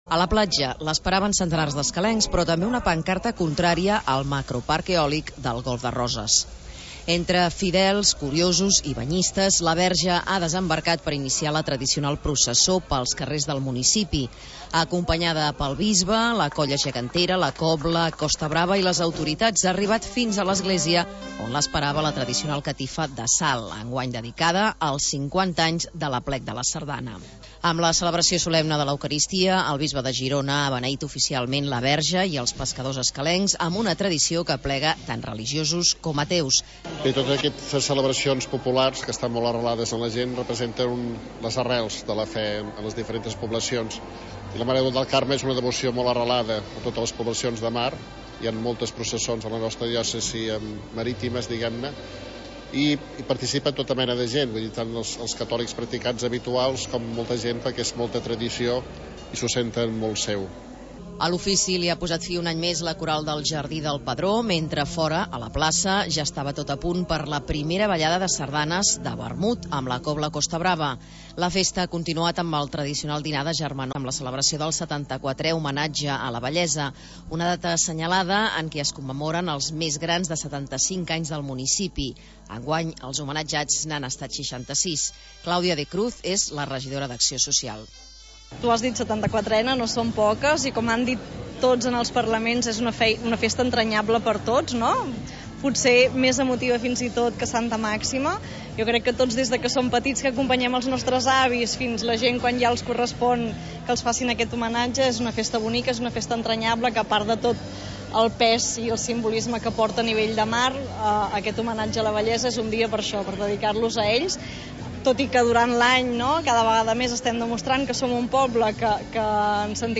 2. L'Informatiu
Ha estat un homenatge a la Vellesa i una festa del Carme acompanyat també de la reivindicació i és que l'Ajuntament ha volgut mostrar el seu suport als pescadors contra la normativa de quotes de la UE- Així ho ha explicat l'alcalde Josep Bofill.